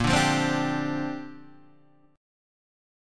A#6add9 Chord